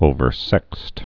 (ōvər-sĕkst)